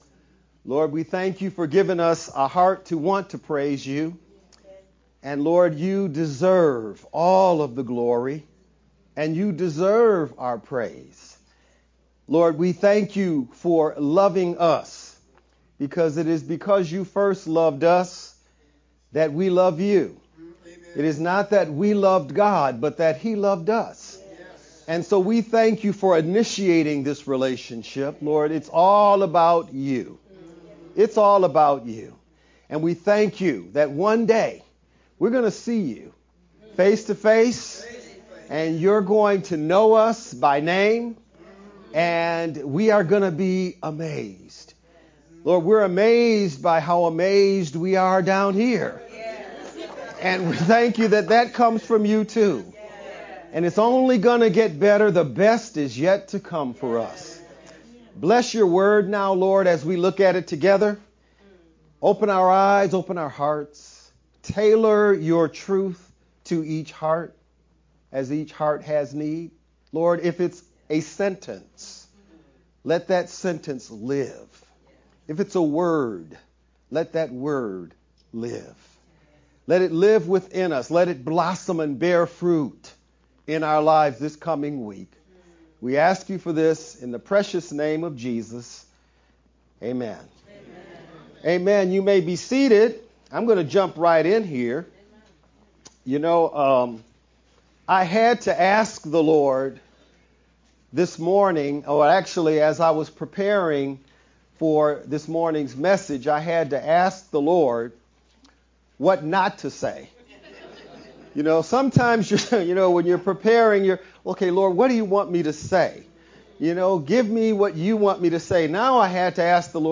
VBCC-edited-sermon-only-Nov-9th_Converted-CD.mp3